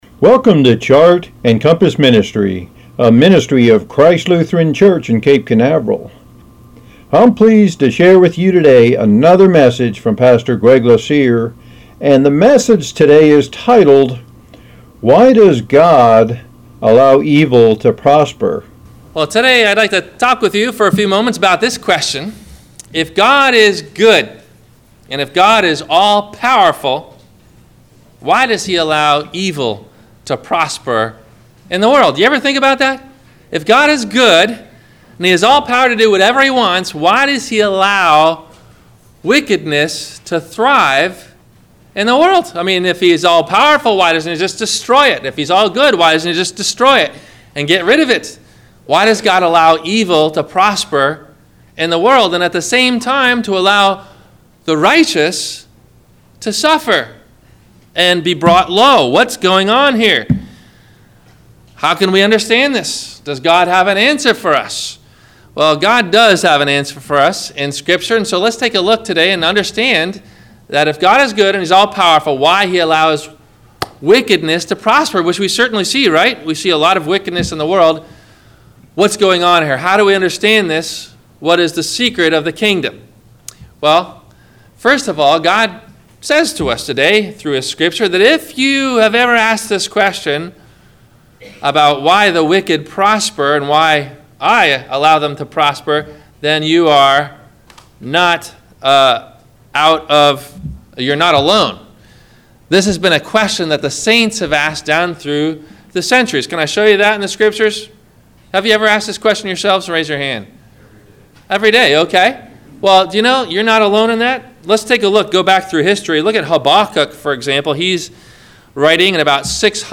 Why Does God Allow Evil to Prosper? – WMIE Radio Sermon – July 15 2019